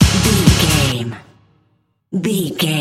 Uplifting
Ionian/Major
drum machine
synthesiser
bass guitar